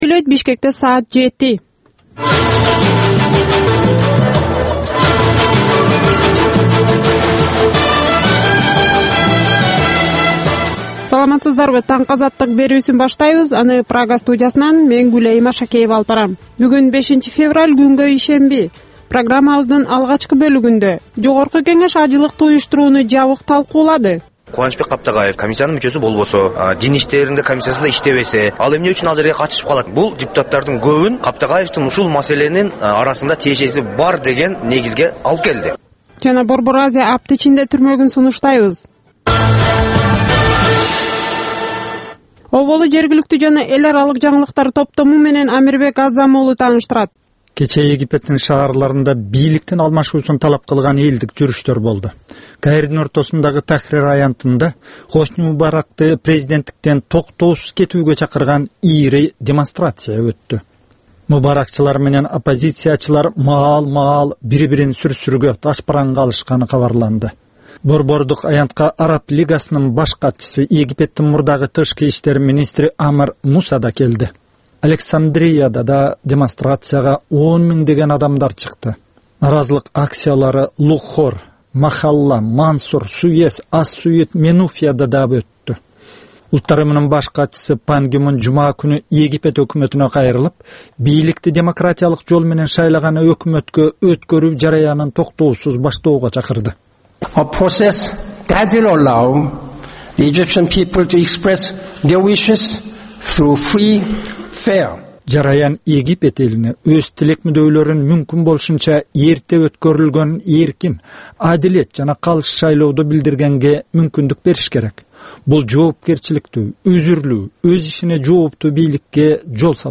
Таңкы 7деги кабарлар